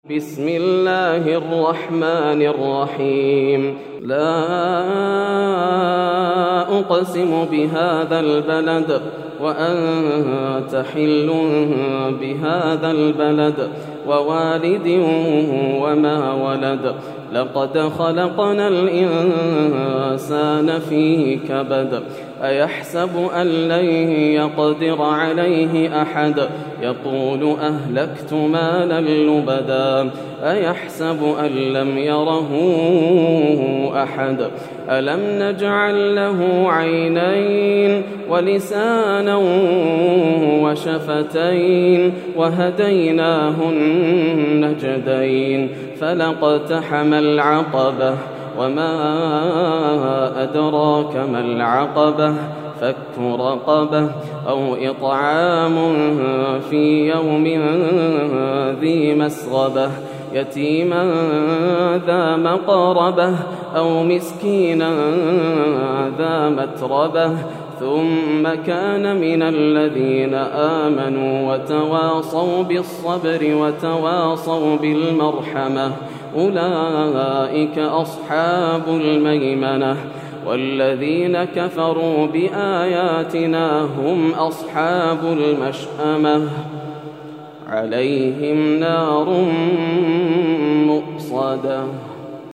سورة البلد > السور المكتملة > رمضان 1431هـ > التراويح - تلاوات ياسر الدوسري